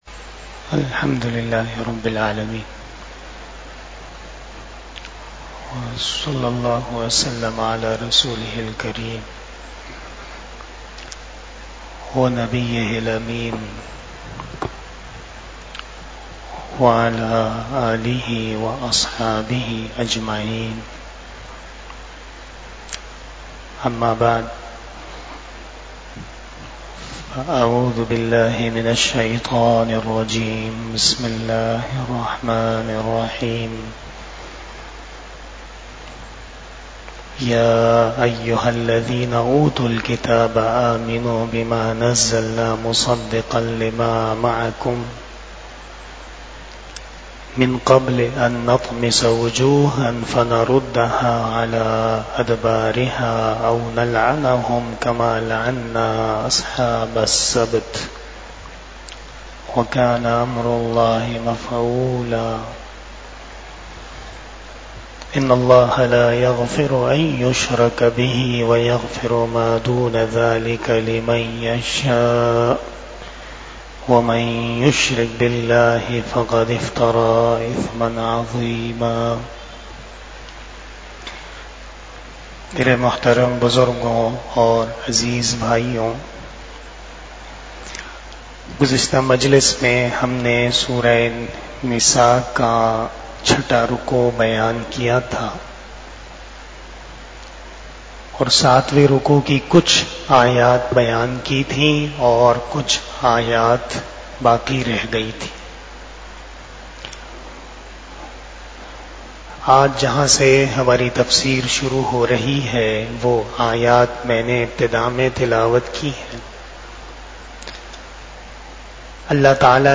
23 Shab E Jummah Bayan 18 July 2024 (12 Muharram 1446 HJ)